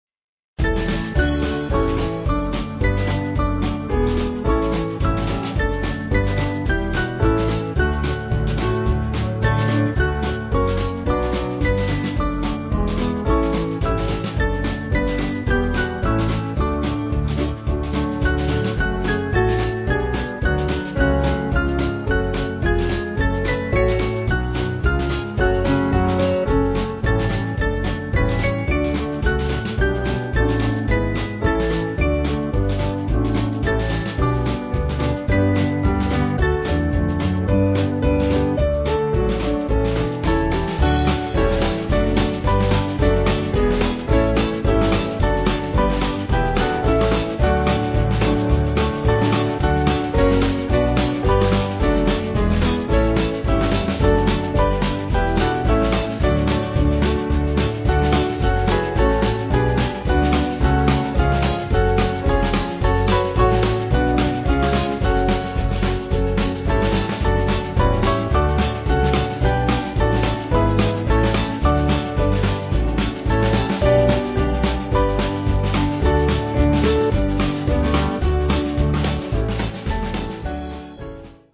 Small Band
4/D-Eb